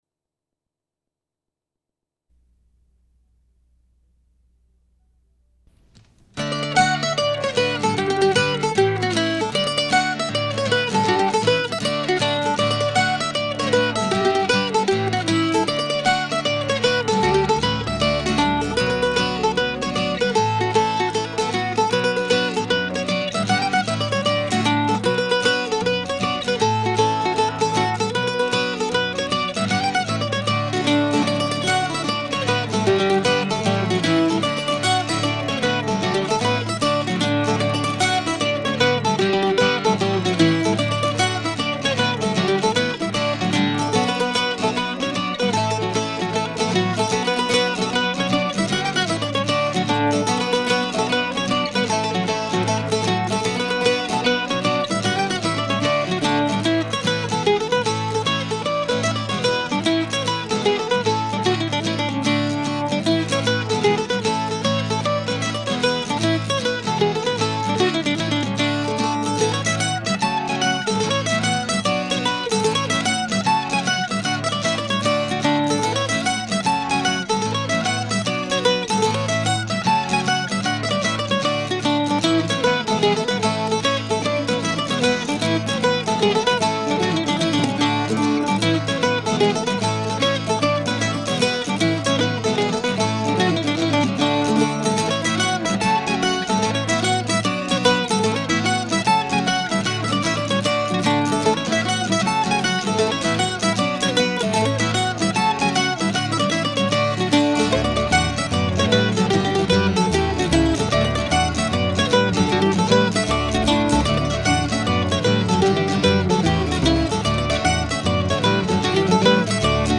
I recorded everything on a then state-of-the-art Fostex four track cassette recorder.
This is all pretty low-fi by today's standards but it was what I had at the time.
The actual recording from 1984 has me playing all the parts. It sounds to me like guitar (probably my old Harmony Sovereign from the mid-60s), mandolin (the same Gibson that I just used this summer for my Deer Tracks CD), octave mandolin (my old Flatiron, in 1984 it was only two or three years old, it's sitting six feet away from me today as I write) and an inexpensive electric bass (long gone).
The bass I would have run directly into the deck, very carefully.
I know that I used the Fostex X-15 (pretty new at the time) to record and I suppose that I mixed the 4 tracks down to stereo using a second cassette deck.